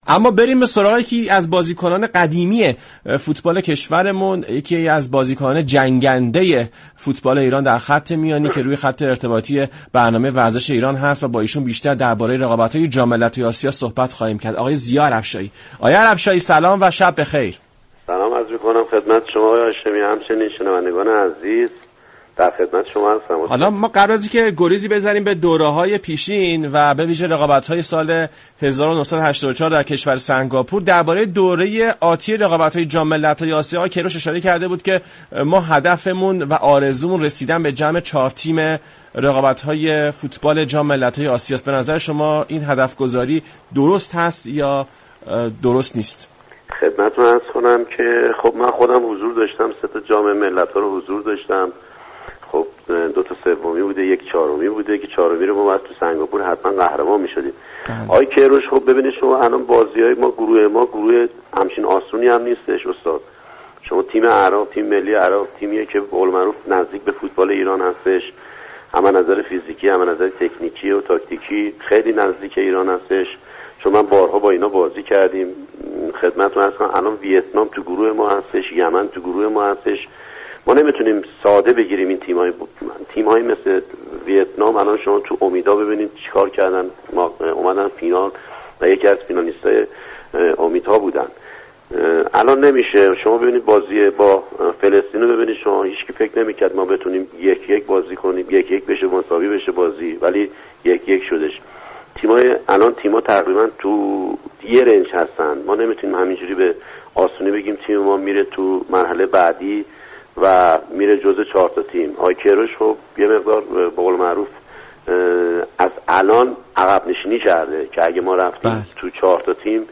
این گفت و گو را می شنویم :